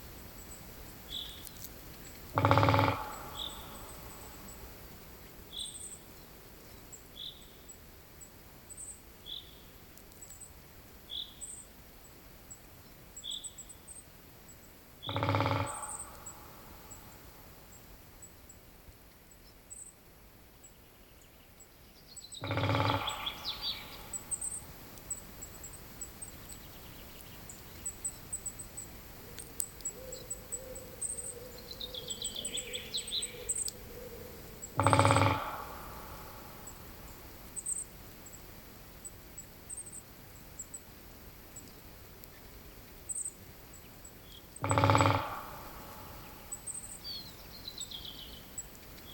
Птицы -> Дятловые ->
большой пестрый дятел, Dendrocopos major
СтатусПоёт